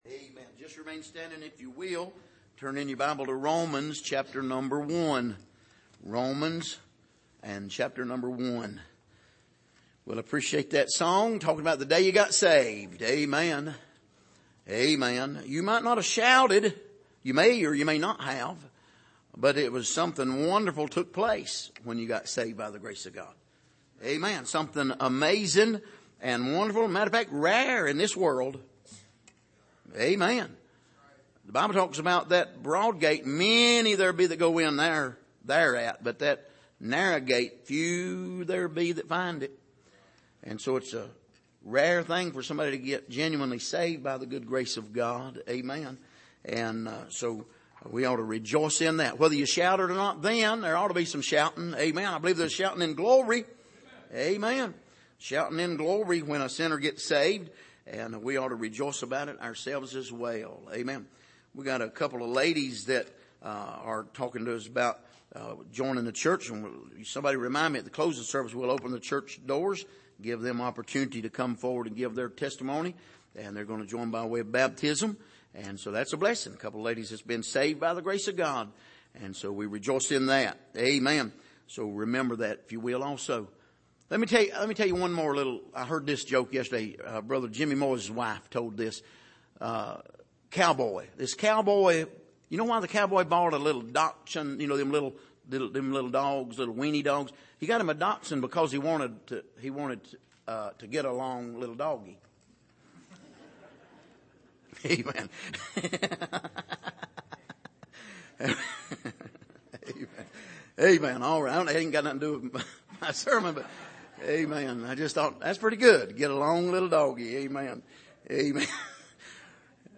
Passage: Romans 1:1-7 Service: Sunday Morning